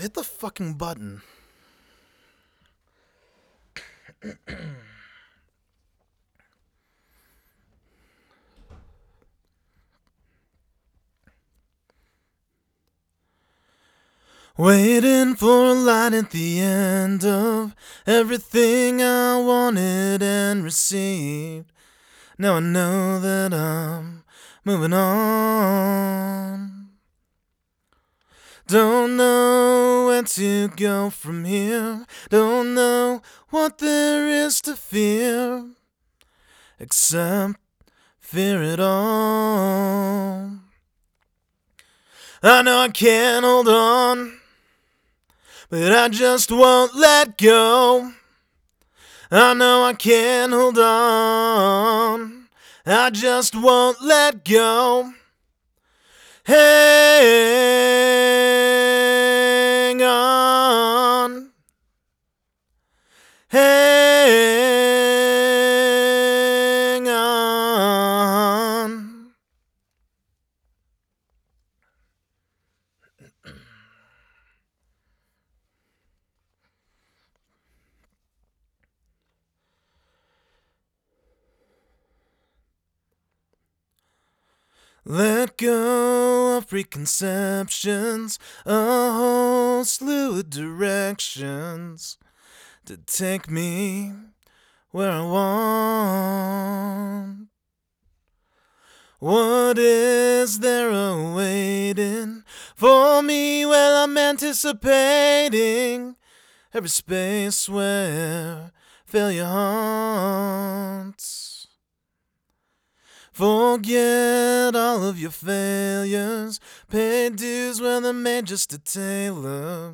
More Vocals_030.wav